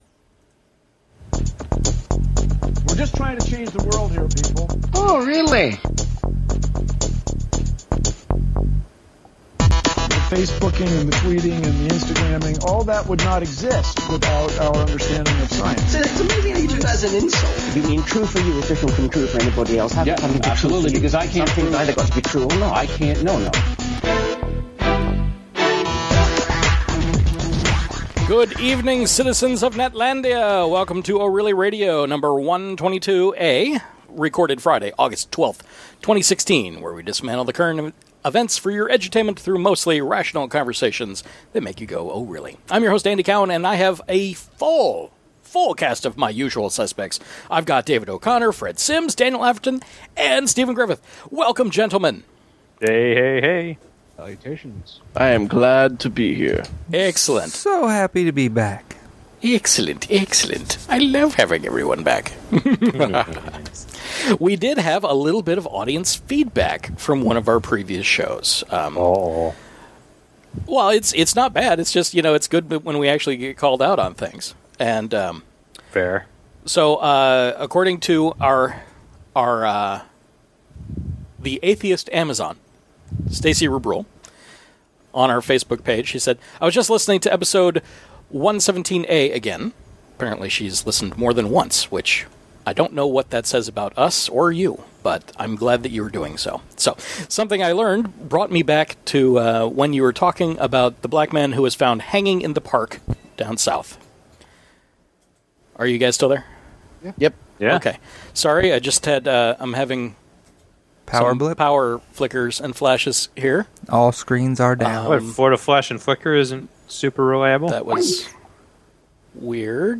Live every Friday night at about 9pm